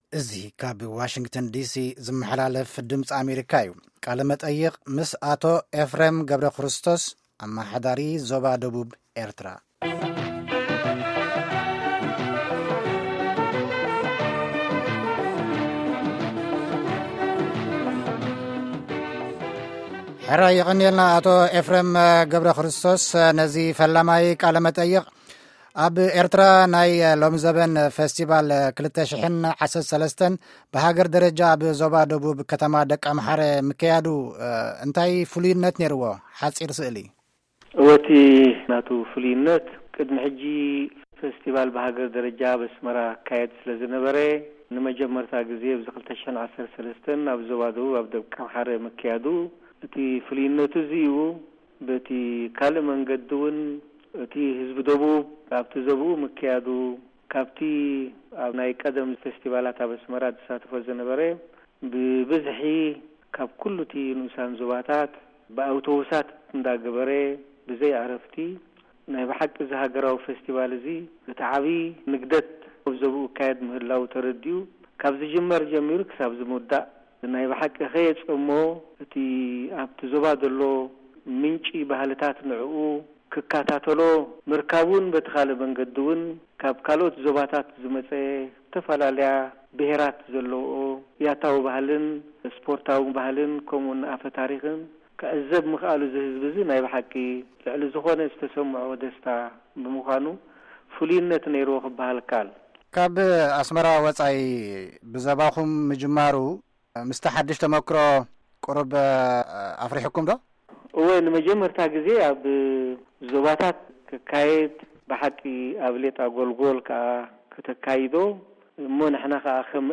ቃለ-መጠይቕ ምስ ሓዲሽ ኣማሓዳሪ ዞባ-ደቡብ -ኤርትራ፡